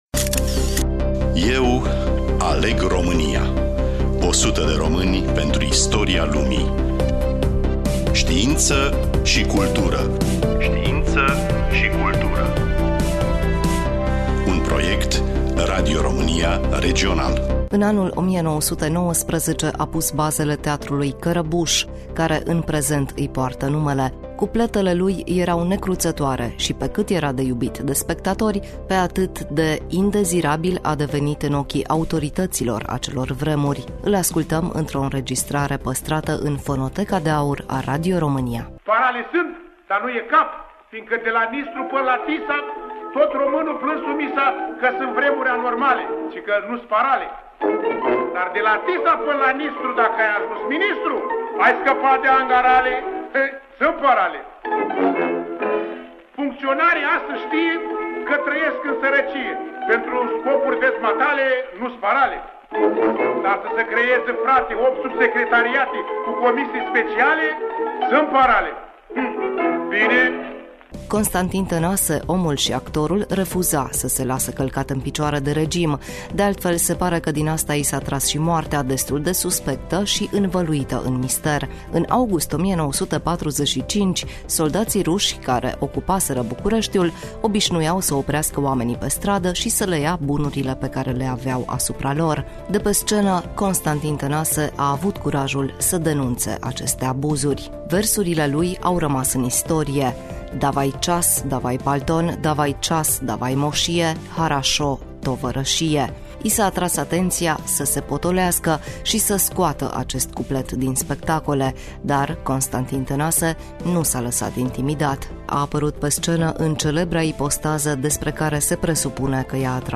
profesor si critic de teatru
reporter